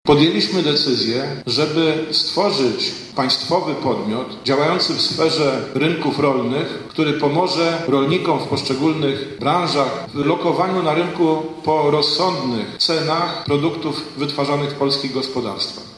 Krajowa Grupa Spożywcza, czyli narodowy holding regulujący rynek rolno-spożywczy, to główny temat spotkania z udziałem wicepremiera Jacka Sasina i ministra rolnictwa i rozwoju wsi Jana Krzysztofa Ardanowskiego w Cukrowni Werbkowice.